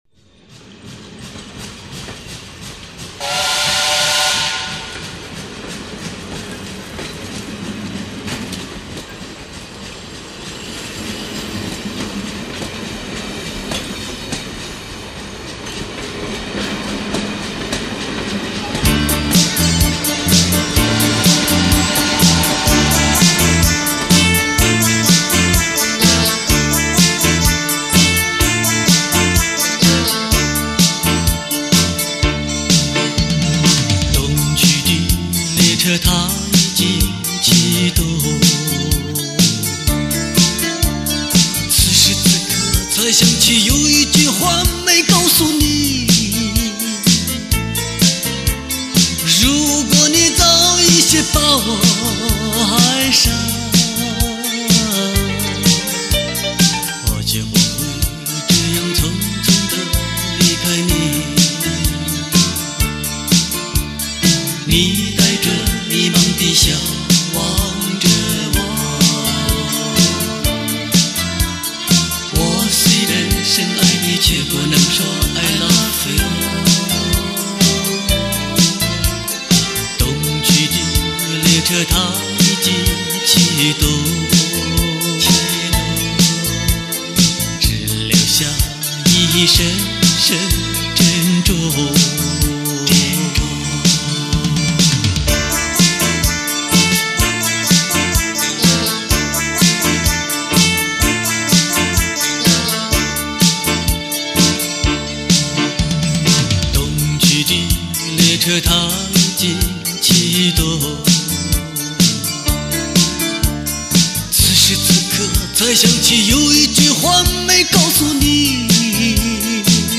发音乐类型: 流行音乐/Pop